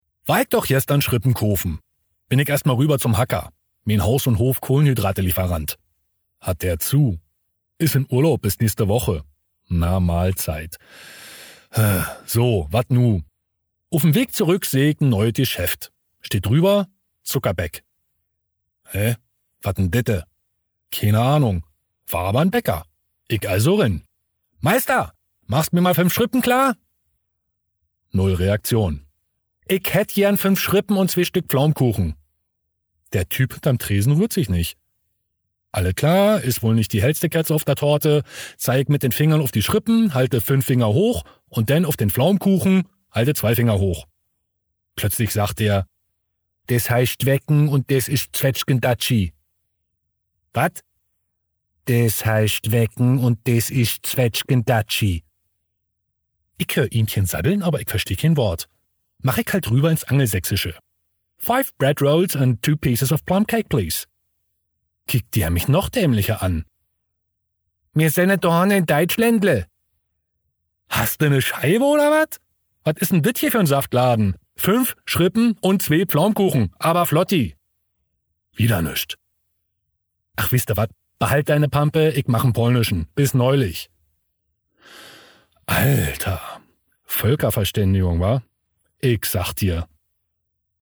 Berlinerisch